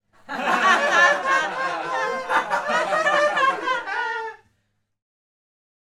laughs.mp3